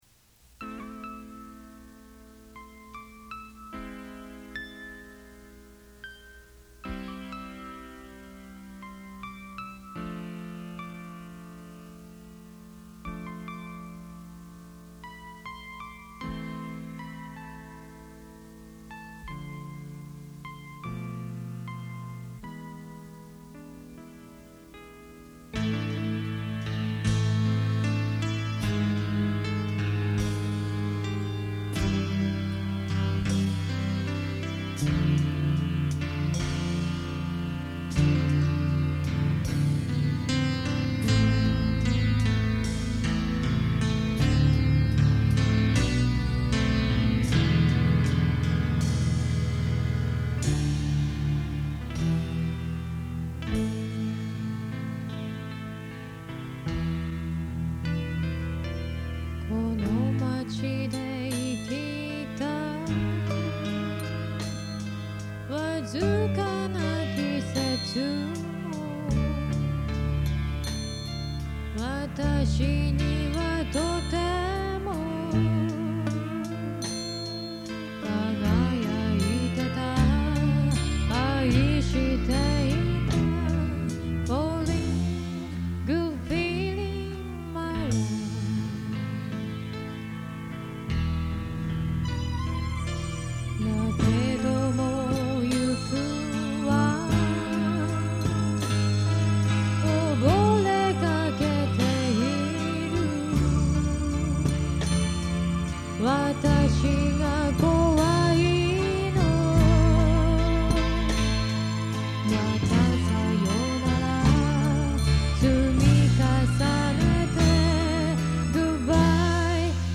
ここで紹介させていただきますのは，もう２０余年前，大学の頃，ひとり軽音楽部の部室で，多重録音で作ったオリジナル曲です。